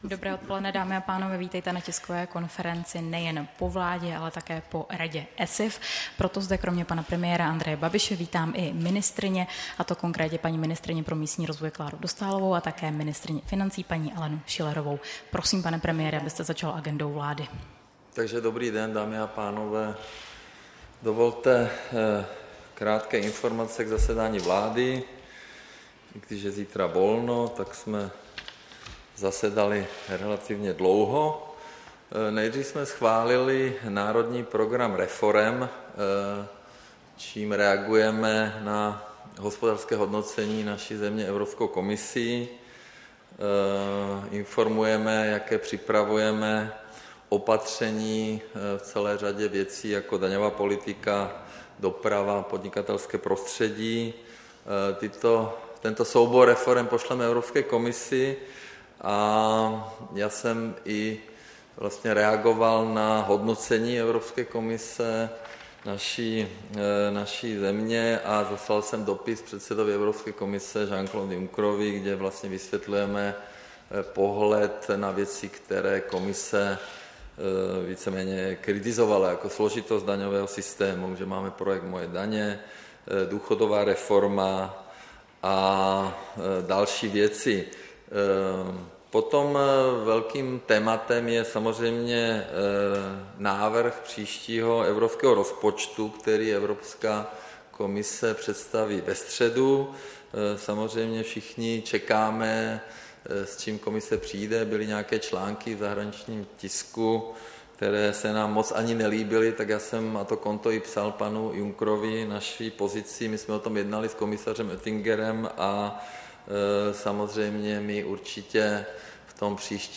Tisková konference po jednání vlády a Rady pro Evropské strukturální a investiční fondy, 30. dubna 2018